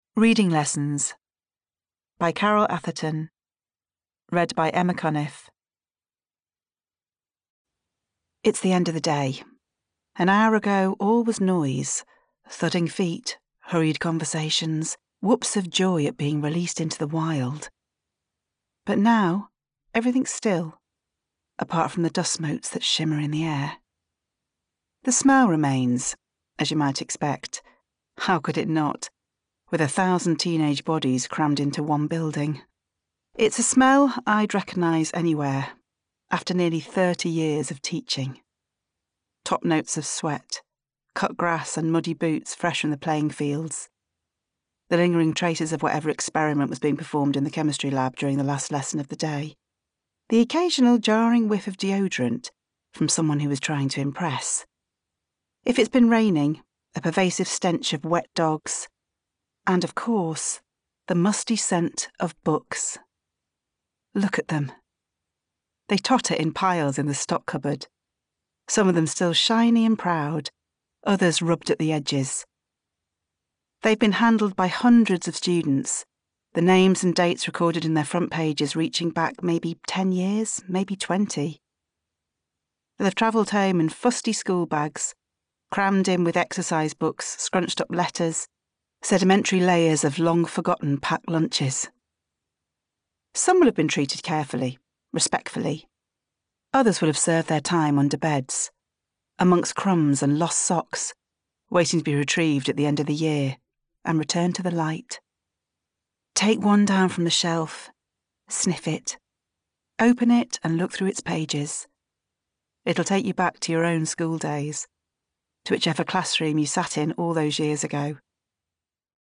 40's Northern,
Reassuring/Warm/Natural
• Audio Books